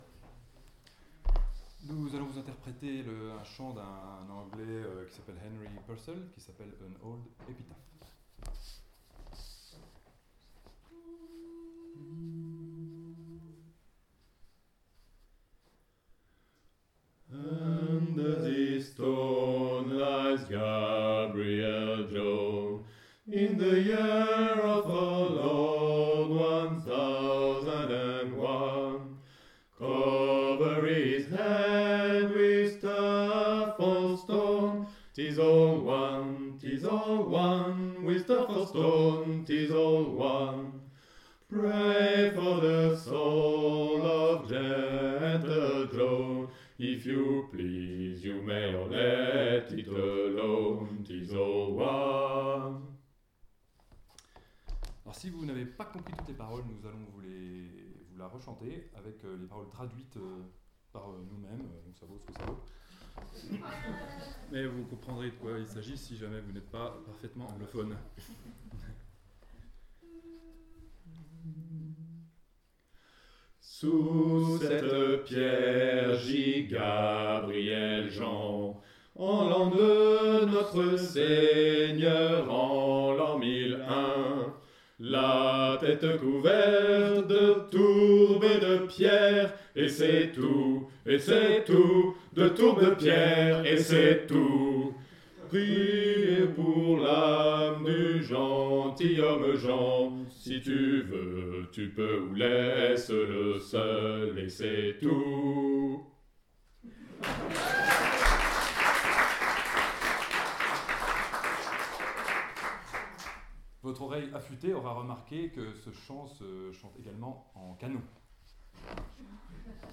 –> Enregistrement avec 2 amis lors d’une résidence, avec traduction française
Canon d’Henry Purcell